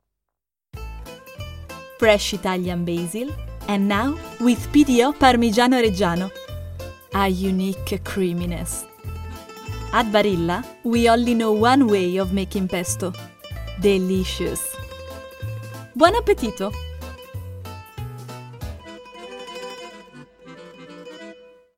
Femelle
Démo commerciale
BrillantEnthousiasteÉnergiqueAmicalNaturelJeune